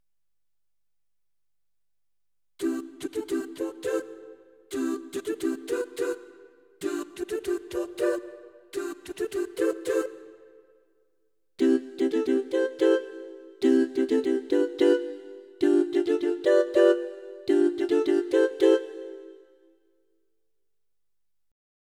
In den folgenden Klangbeispielen spiele ich zuerst immer die Yamaha Genos Voice, danach die V3 YAMMEX Sounds.
Mehr Details im Genos Sound vom Alt-Saxophon, dafür mehr Substanz im Ton beim YAMMEX.